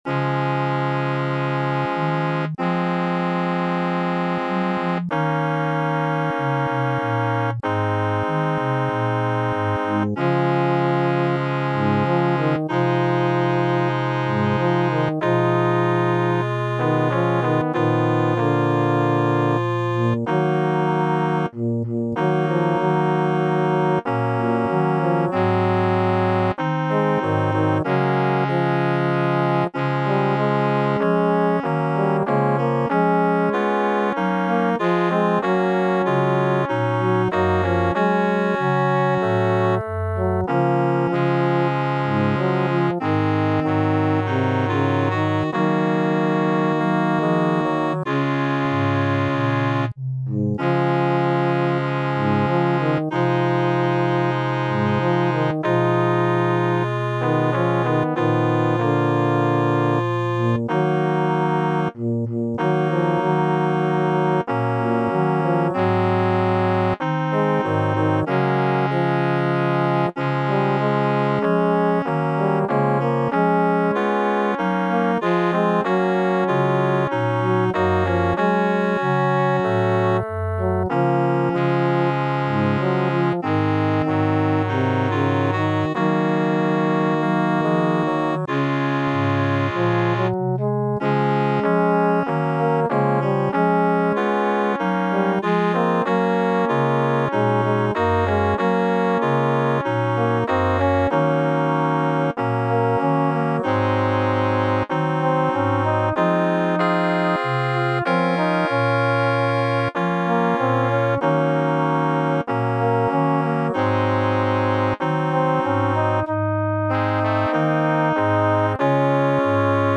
Ballade.
Caractère de la pièce : contemplatif
Solistes : Tenor / Bariton (1 soliste(s))
Tonalité : ré bémol majeur